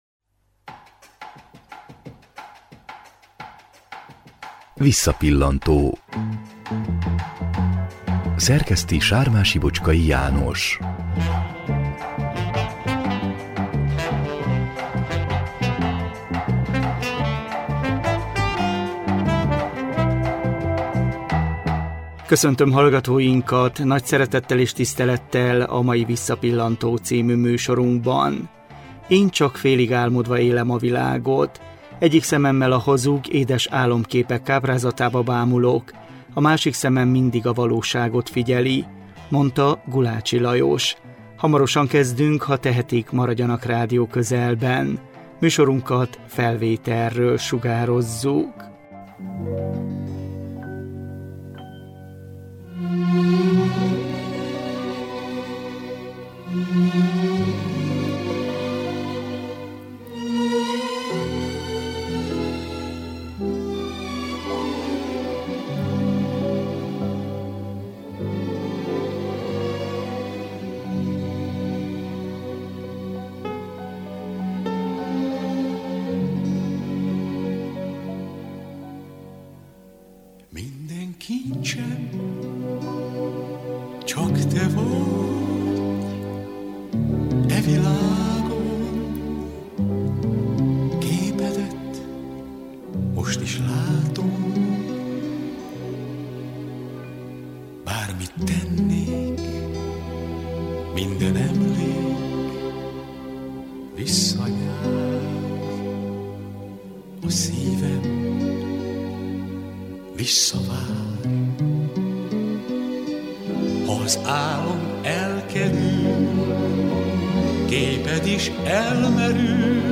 több zenekarban is kipróbálta tehetségét. 2007-ben készült vele zenés portré.